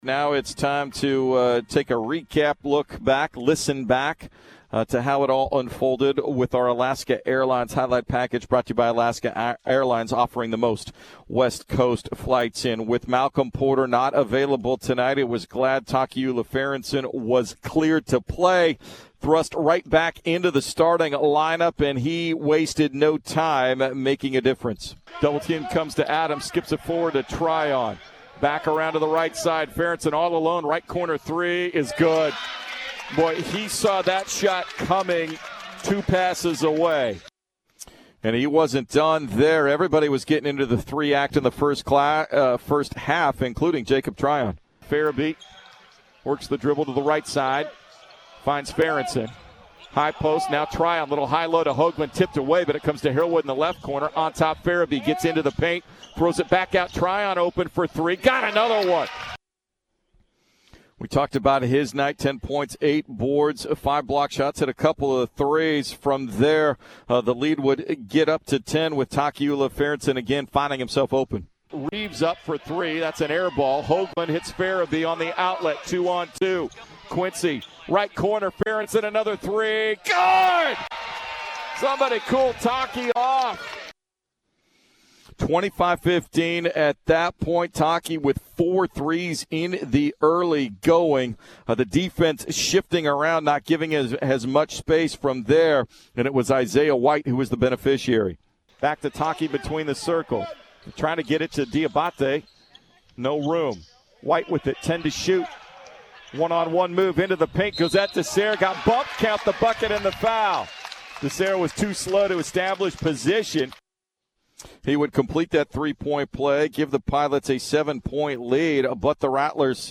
December 16, 2019 Radio highlights from Portland's 66-60 win against Florida A&M on Monday, Dec. 16, 2019.